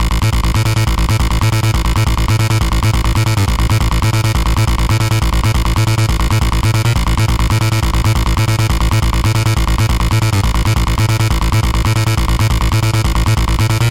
合成器恍惚的低音 01
描述：合成低音
Tag: 140 bpm Trance Loops Bass Loops 1.15 MB wav Key : Unknown